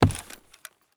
46265b6fcc Divergent / mods / Soundscape Overhaul / gamedata / sounds / material / human / step / new_wood4.ogg 34 KiB (Stored with Git LFS) Raw History
new_wood4.ogg